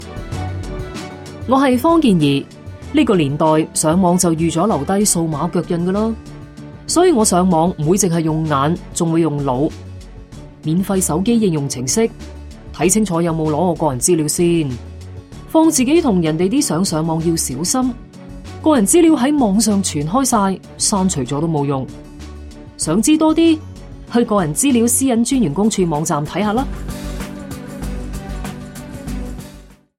电台宣传声带